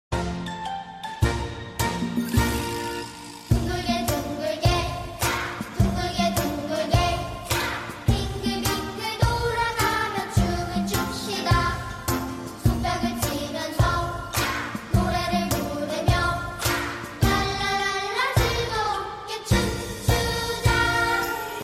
K-Pop File format